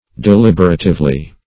Search Result for " deliberatively" : The Collaborative International Dictionary of English v.0.48: Deliberatively \De*lib"er*a*tive*ly\, adv.